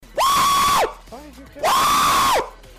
Tyler1 Screaming